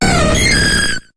Cri de Mentali dans Pokémon HOME.